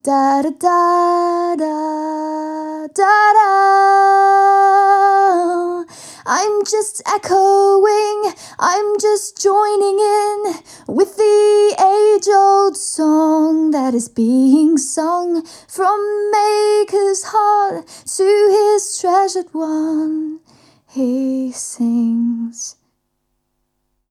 Échantillons sonores Audio Technica AE-2500
Audio Technica AE-2500 mikrofon - damski wokal